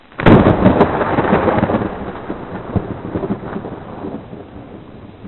دانلود صدای رعد و برق مخصوص زنگ موبایل از ساعد نیوز با لینک مستقیم و کیفیت بالا
جلوه های صوتی